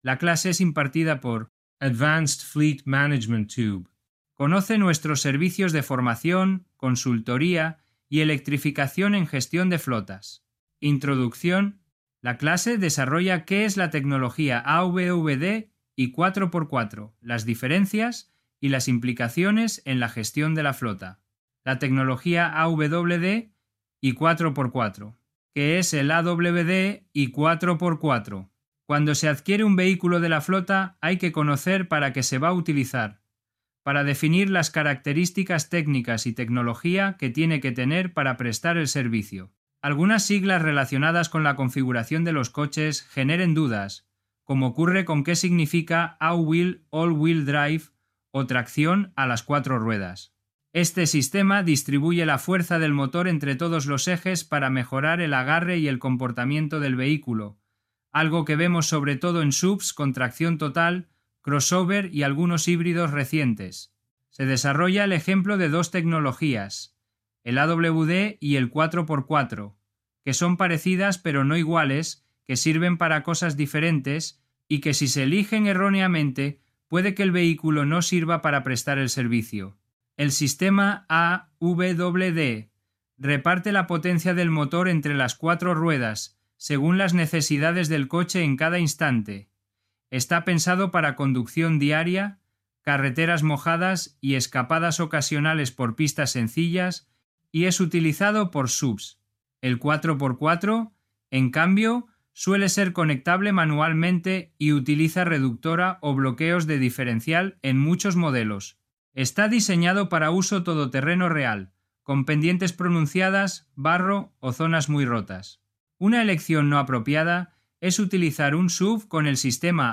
La clase desarrolla que es la tecnología AWD y 4x4, las diferencias, y las implicaciones en la gestión de la flota.